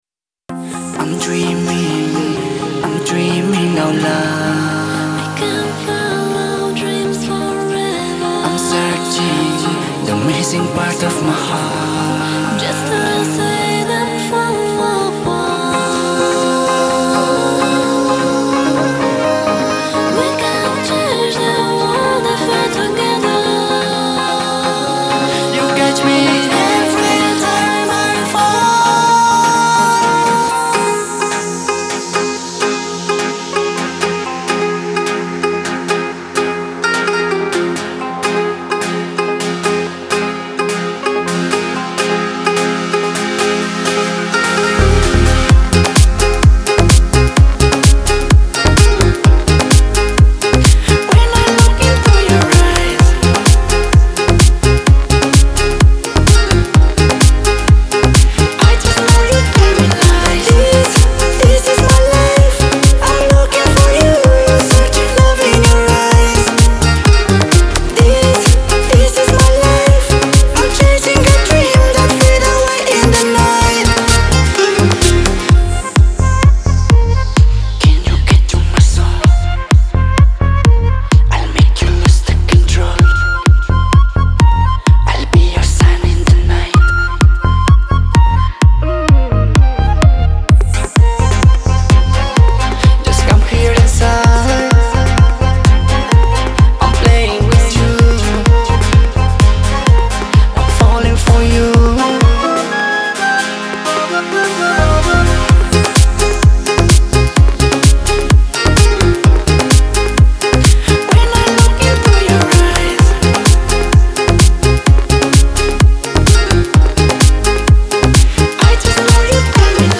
• Жанр: Dance, Pop